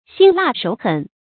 心辣手狠 注音： ㄒㄧㄣ ㄌㄚˋ ㄕㄡˇ ㄏㄣˇ 讀音讀法： 意思解釋： 見「心狠手辣」。